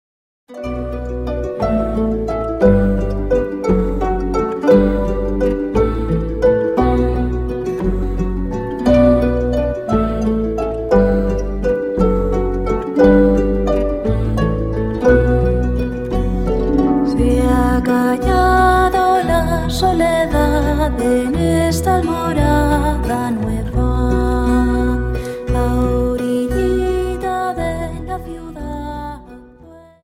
Dance: Viennese Waltz 58 Song